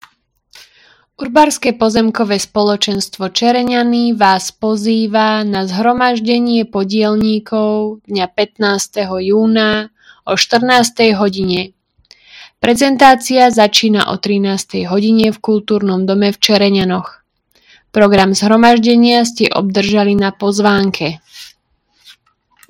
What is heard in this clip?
Hlásenie obecného rozhlasu – Stretnutie podielnikov UPS Čereňany